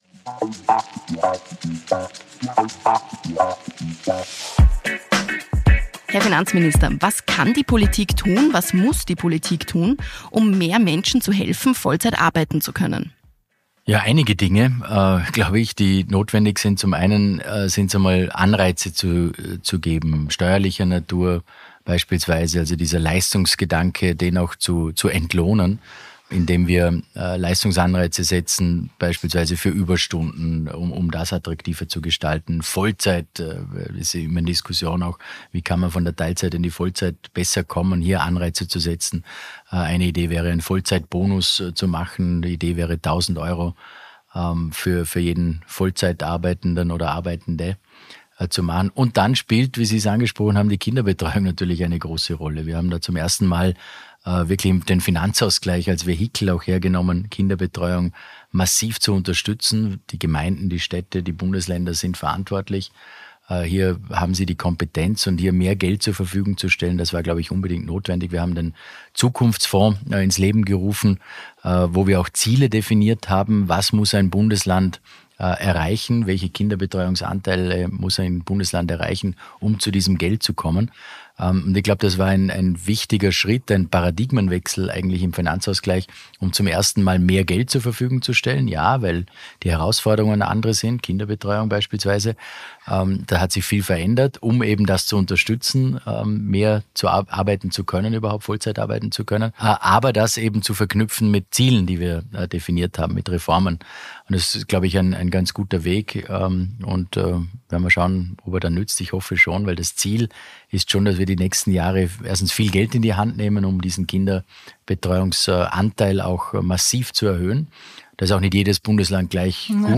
Finanzminister Magnus Brunner
Im Gespräch